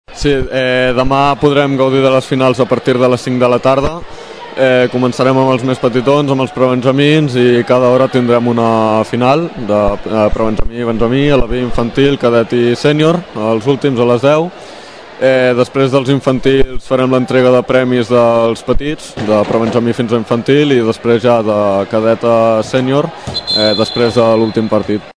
Ahir en declaracions al programa la Banqueta d’estiu